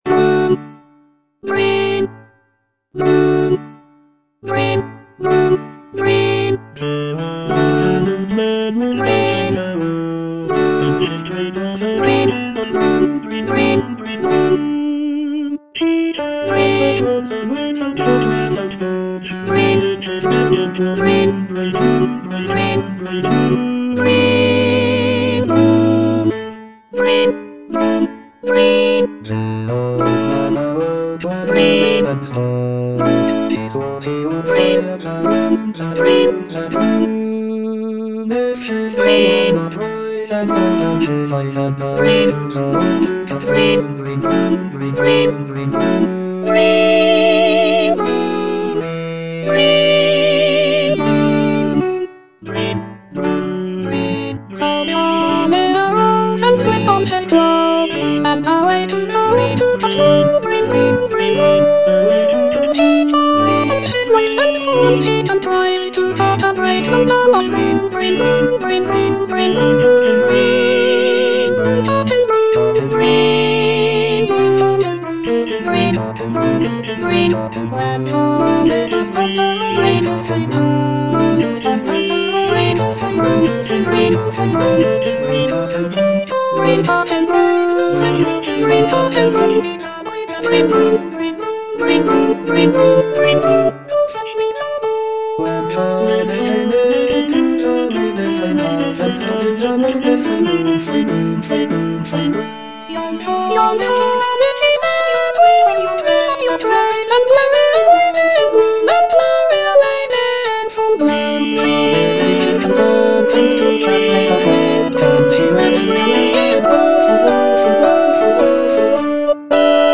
Soprano II (Emphasised voice and other voices)